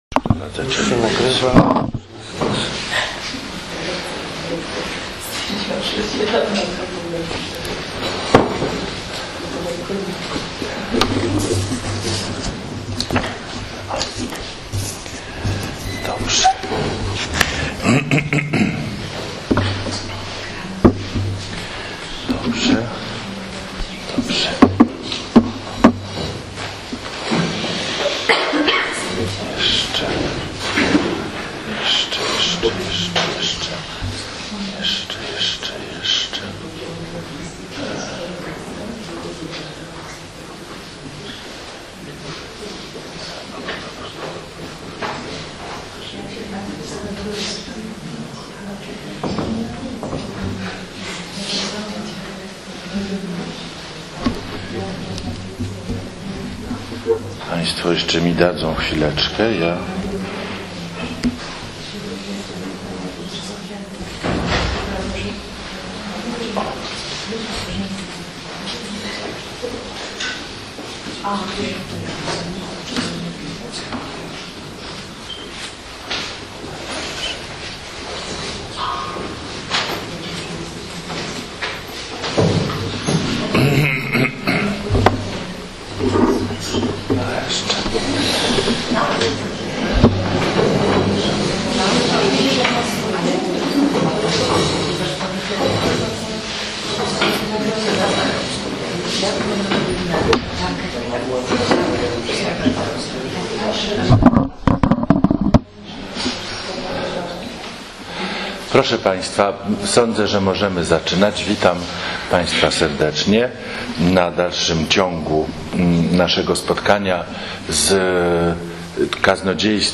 ZACHĘCAMY DO ODSŁUCHANIA POPRZEDNICH WYKŁADÓW